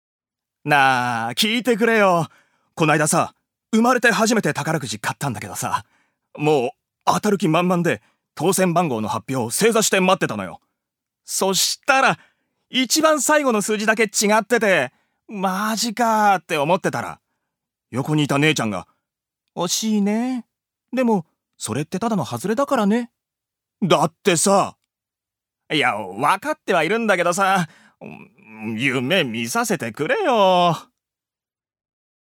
所属：男性タレント
セリフ５